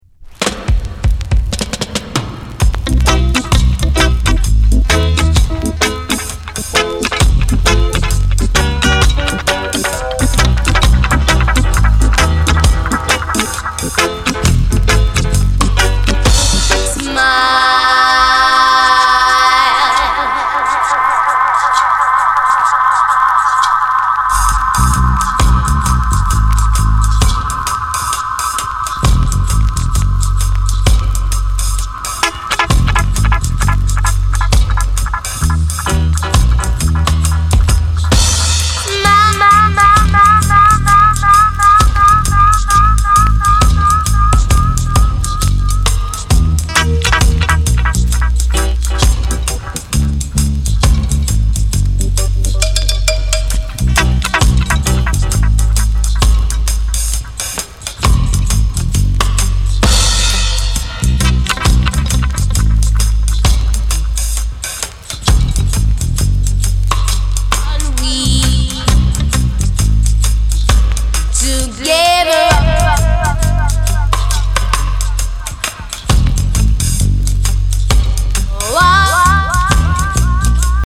Genre:  Lovers Rock